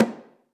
AFRO.TAMB4-S.WAV